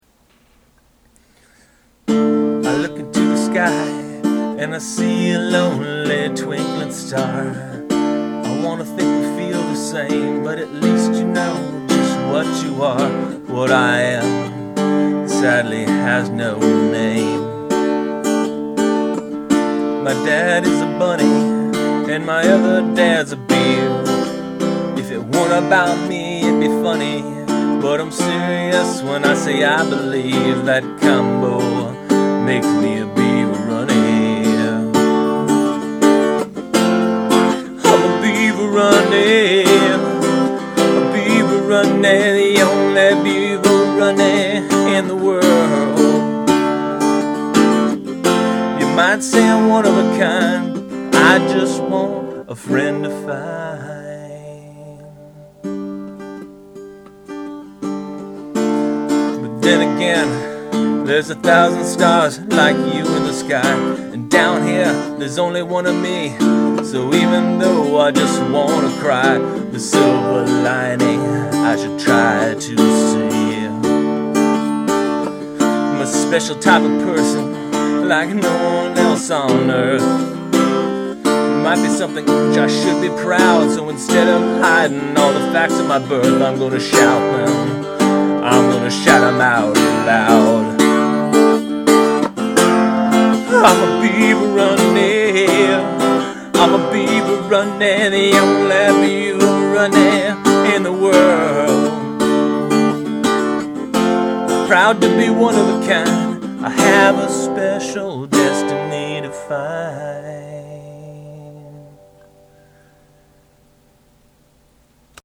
demo track of the above song